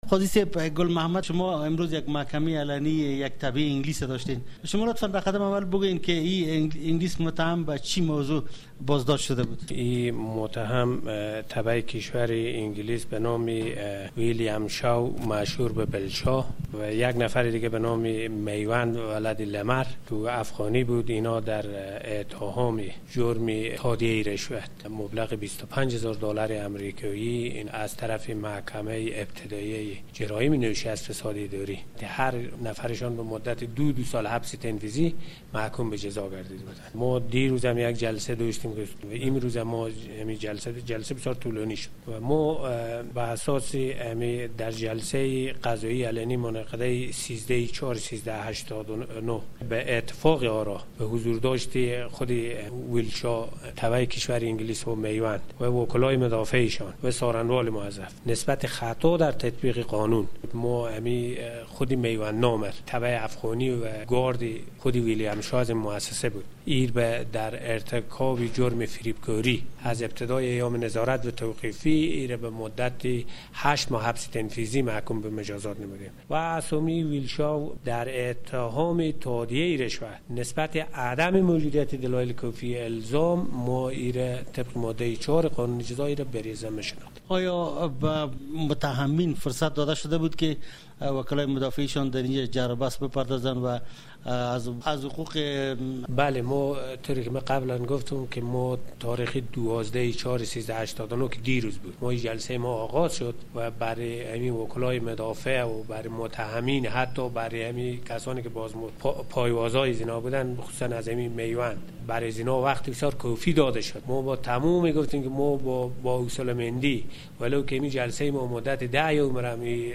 مصاحبه با قاضی گل محمد در مورد محکمهء یک تبعهء بریتانیایی و یک افغان